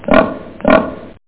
PIG.mp3